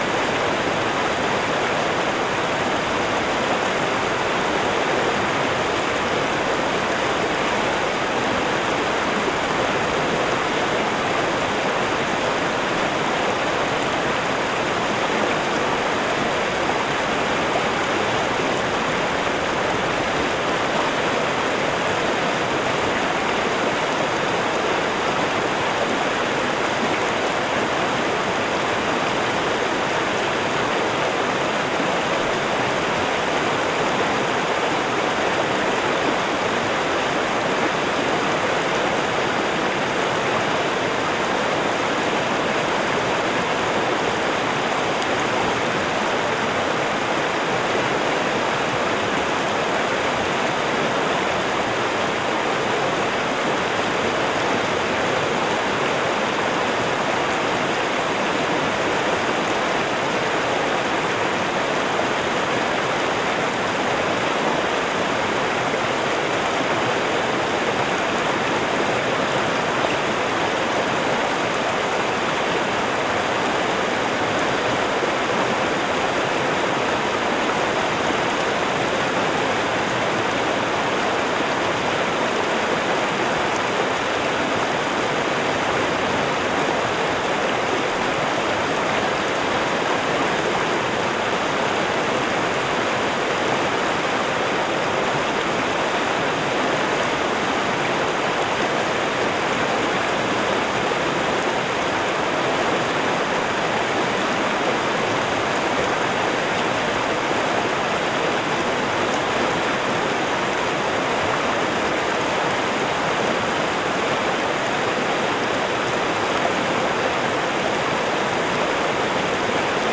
river-3.ogg